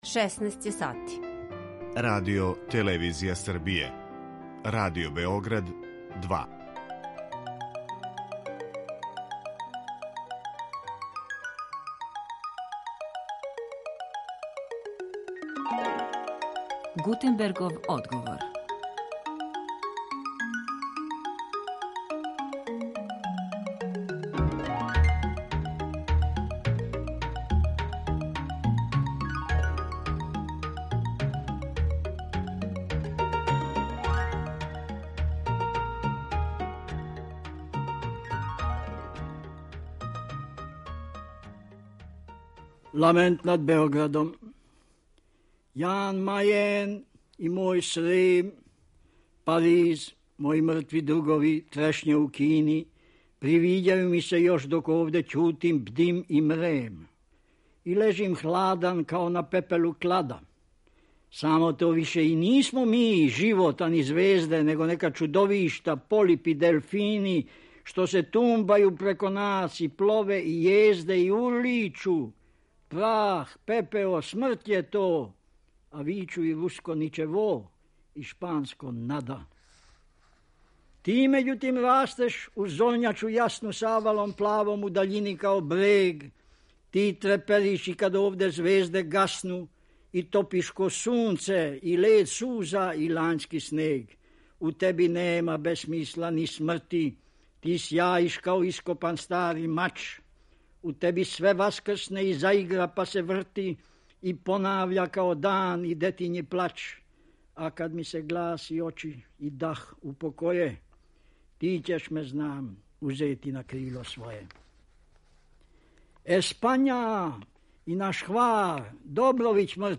У Гутенберговом одговору, уз „Ламент над Београдом" који говори сам Црњански, присетићемо се и његових речи о улози писца и критичара у интерпретацији књижевног дела, те чути како данас поједини истраживачи тумаче његову поезију - од општих и специфичнијих погледа на мит, традицију и симбол до родних читања његове лирике или деликатног питања посвећеног мотивима религиозног. Чућемо и шта је карактеристично за лексички поетизам лепрш, и какав интертекстуални дијалог Црњански путем ове речи успоставља са песничком традицијом.